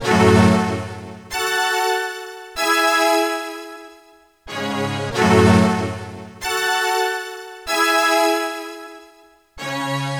Ridin_ Dubs - Strings.wav